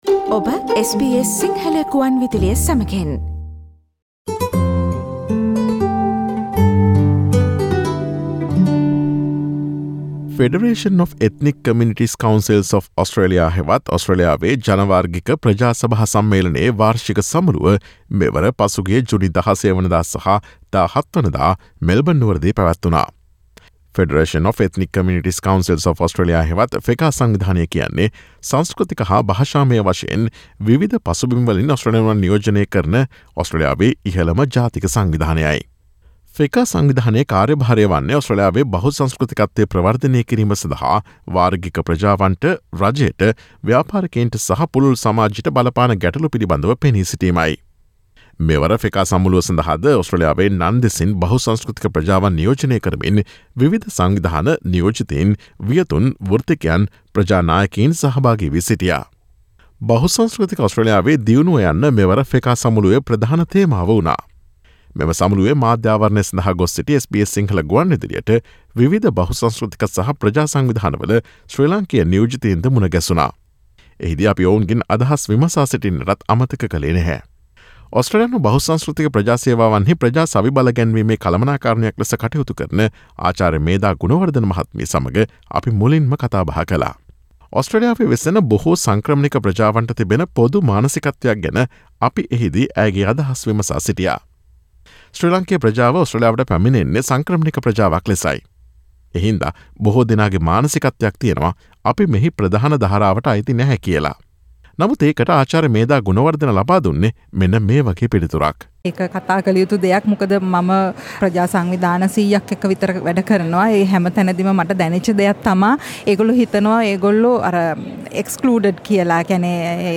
ඔස්ට්‍රේලියාවේ වෙසෙන ශ්‍රී ලාංකේය ප්‍රජාව ඔස්ට්‍රේලියාවේ ප්‍රධාන සමාජ ධාරාවට අයිති නැතිද යන ආකල්පය පිළිබඳ 2022 ජුනි 16 සහ 17 දිනවල මෙල්බර්න් නුවරදී පැවති FECCA සමුළුවේදී ශ්‍රී ලාංකේය ප්‍රජා නියෝජිතයෝ SBS සිංහල ගුවන් විදුලියට දැක්වූ අදහස්වලට සවන් දෙන්න.